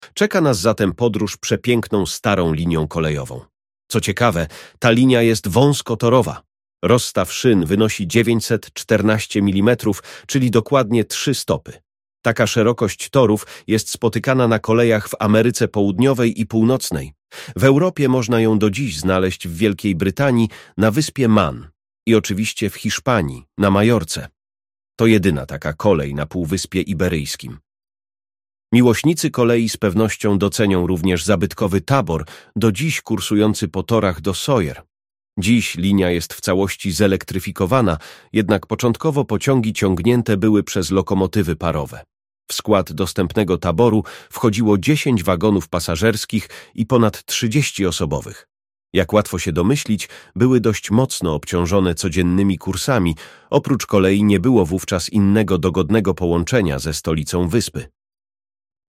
Tu macie fragment naszego audioprzewodnika.